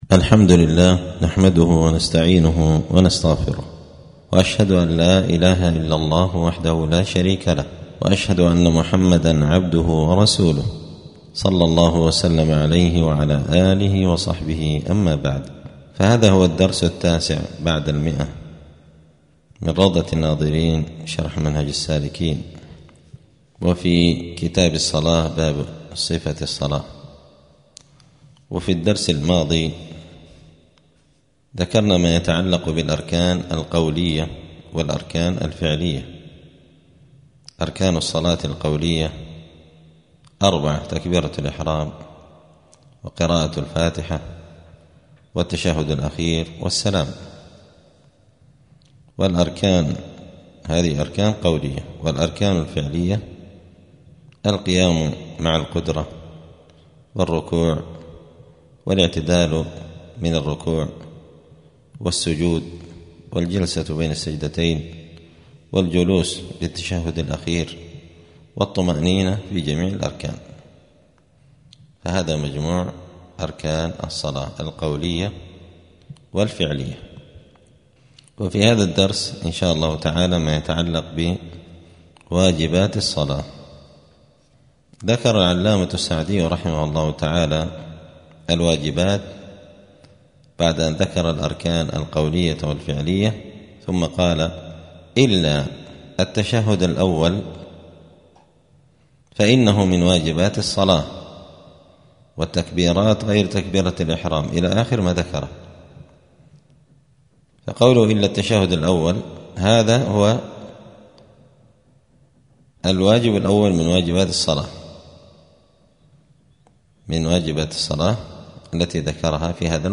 *الدرس التاسع بعد المائة (109) {كتاب الصلاة باب صفة الصلاة واجبات الصلاة}*